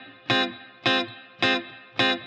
DD_TeleChop_105-Fmin.wav